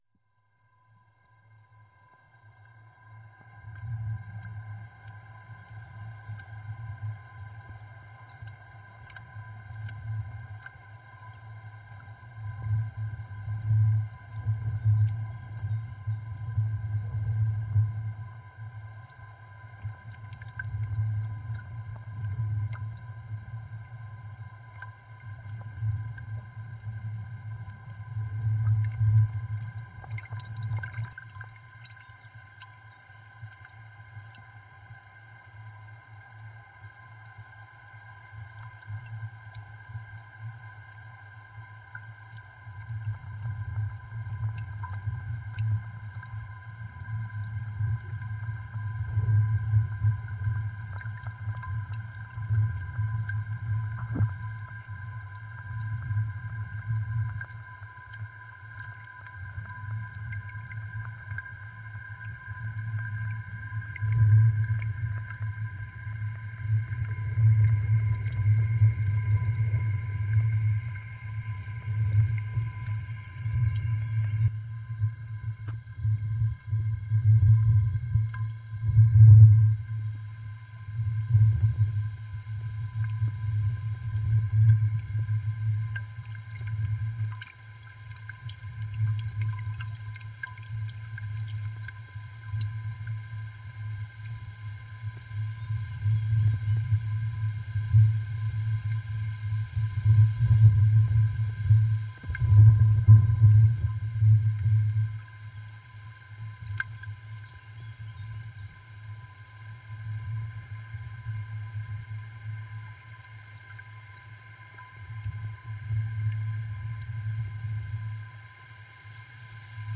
[:pt]Gravação com microfones dentro de garrafas parcialmente submersas.
Tipo de Prática: Arte Sonora
Ameixiosa-Microfones-Dentro-de-Garrafas-Submersas-1.mp3